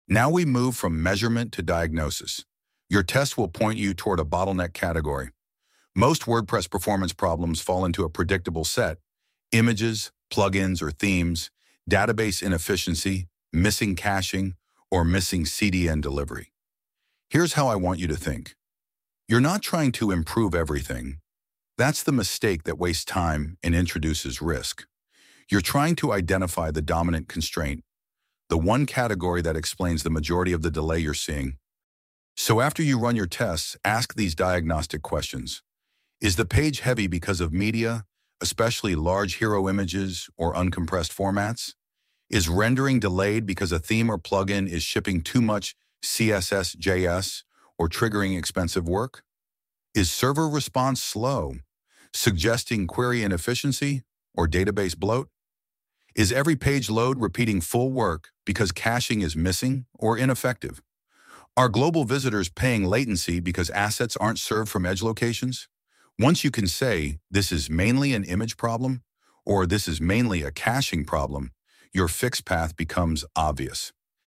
Lesson-2.1-Step-3-VO-full.mp3